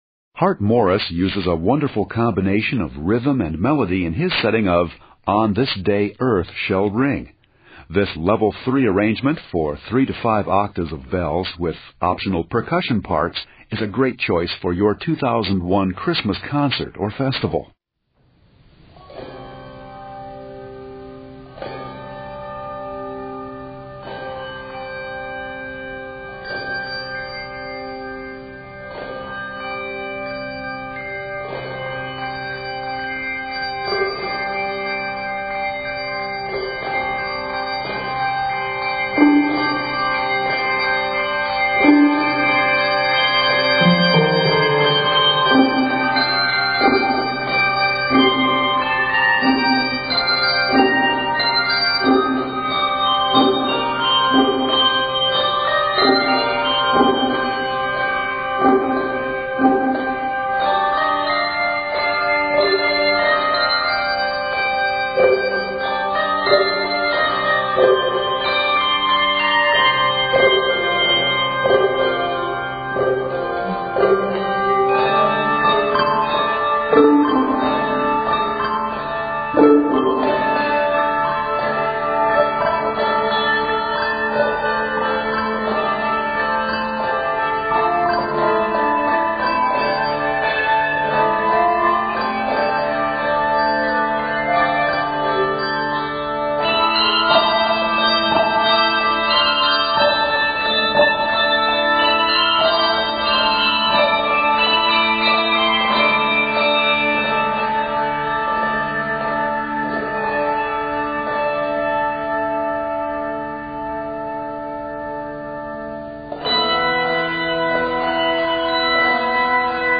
cheerful setting